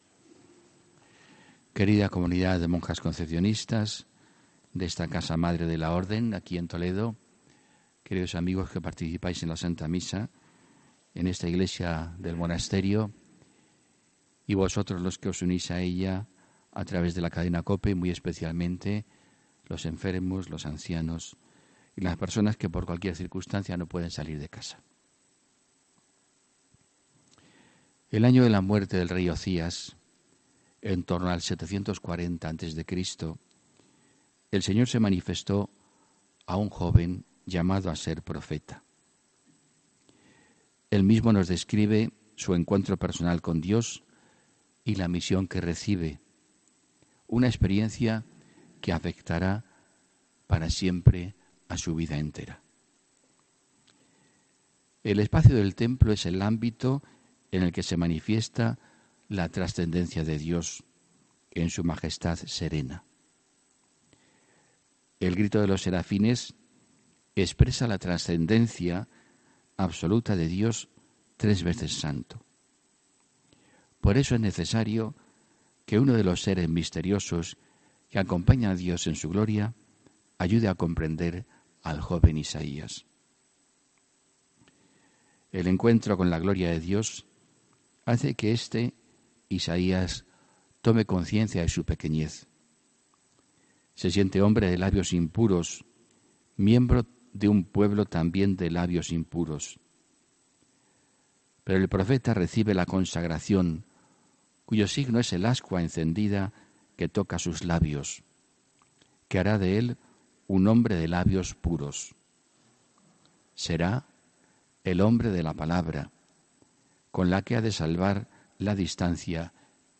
HOMILÍA 10 FEBRERO 2019